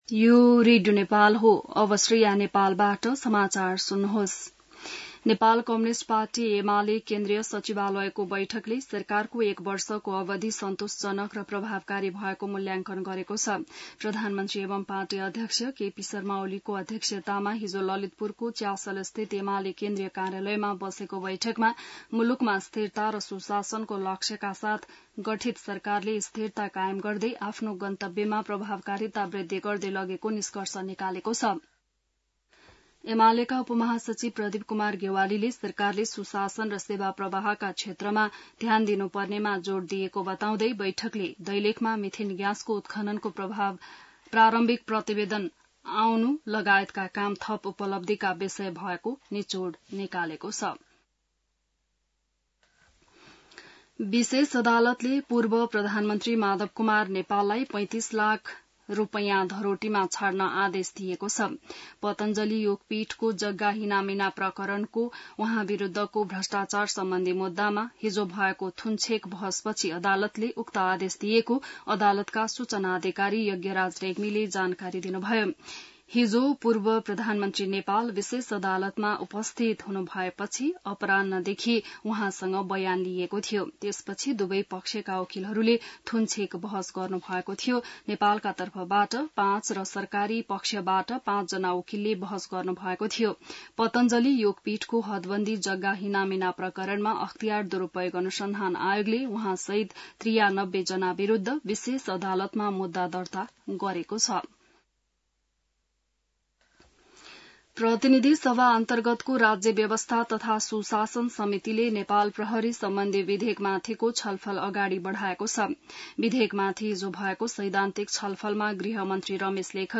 बिहान ६ बजेको नेपाली समाचार : १२ असार , २०८२